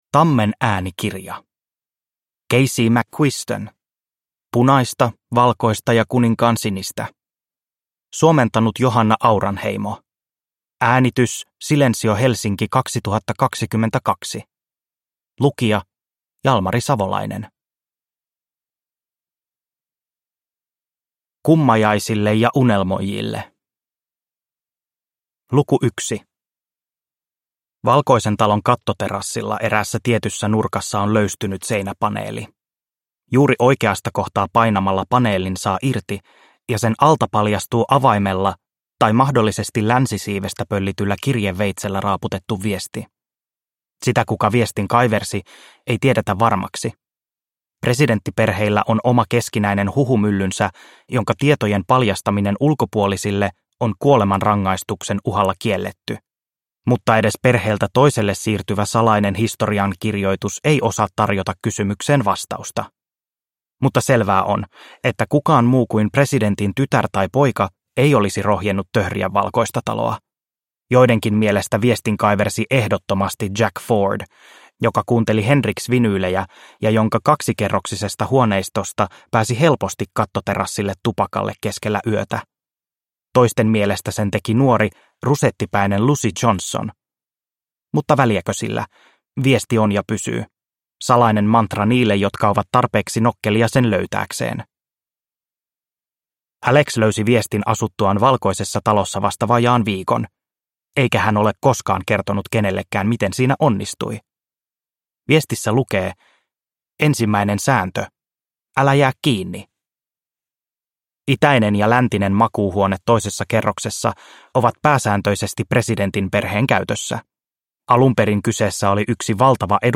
Punaista, valkoista ja kuninkaansinistä – Ljudbok – Laddas ner